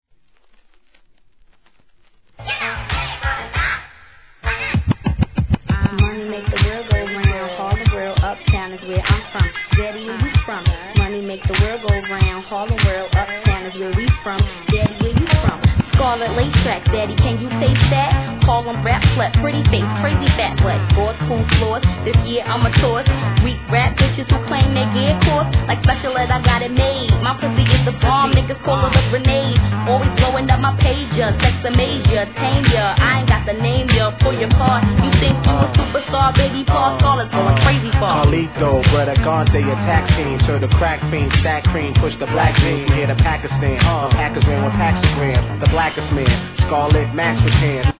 HIP HOP/R&B
'90s フィメール・インディーRAP!